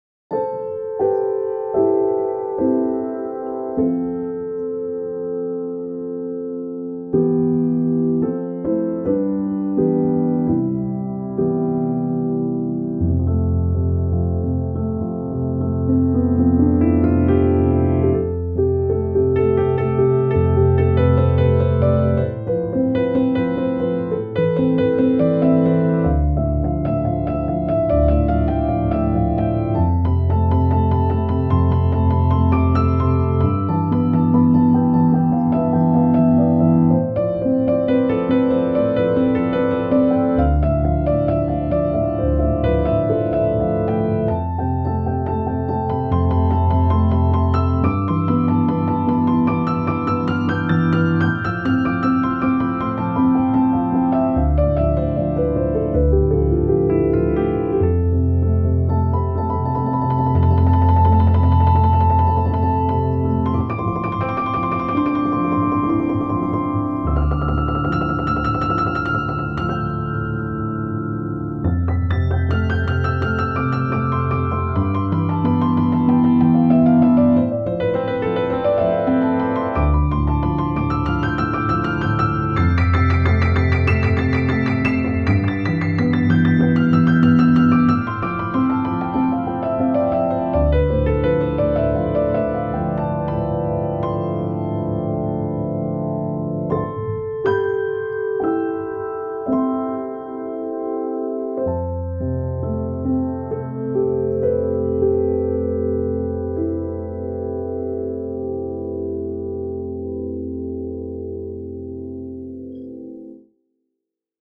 موسیقی بی کلام آرامش بخش پیانو مدرن کلاسیک